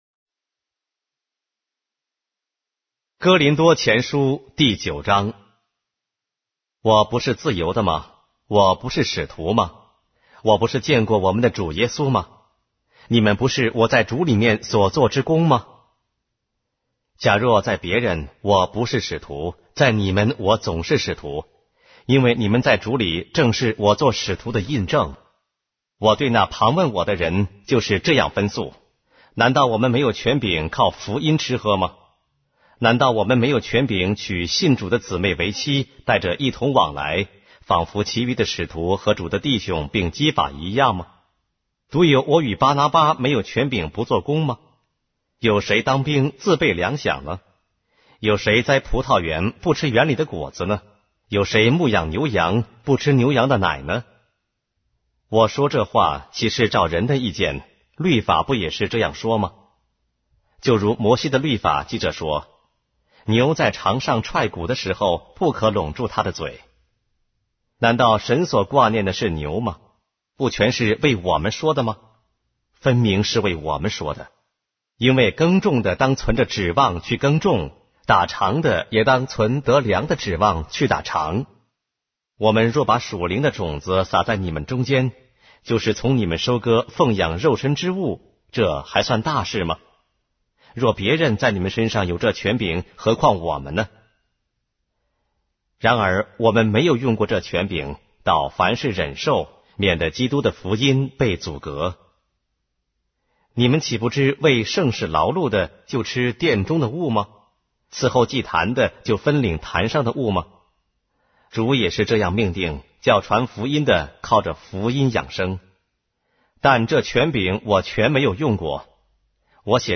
书的圣经 - 音频旁白 - 1 Corinthians, chapter 9 of the Holy Bible in Simplified Chinese